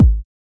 The following examples use the following three Roland TR808m drum machine sound samples from FreeSound:
bass drum
bd.wav